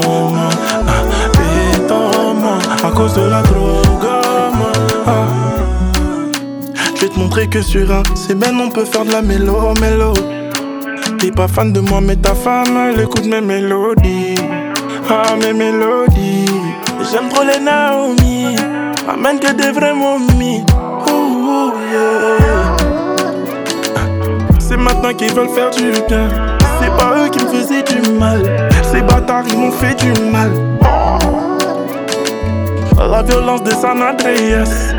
Жанр: Африканская музыка / Поп
# Afro-Pop